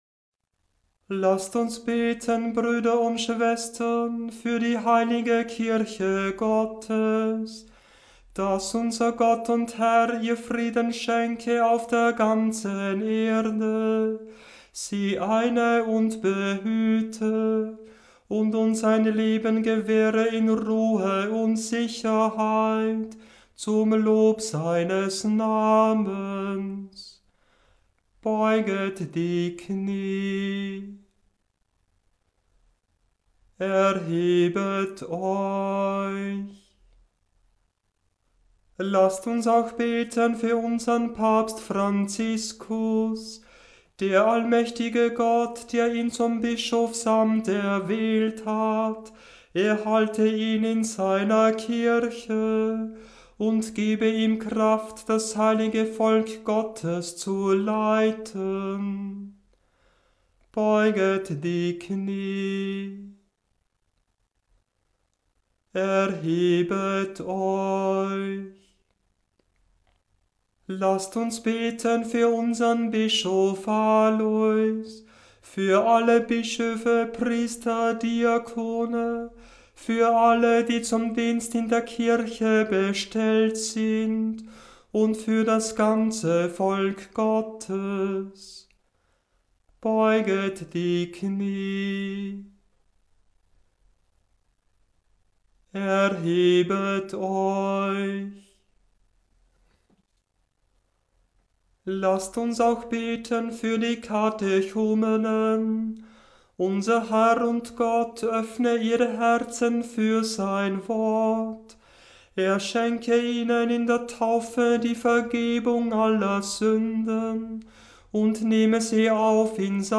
Liturgische Gesänge